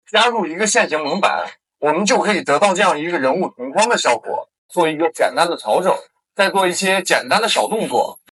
IA de voix-off marketing professionnelle
Voix-off marketing
Narration publicitaire